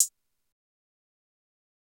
Closed Hats
CrazY HiHat.wav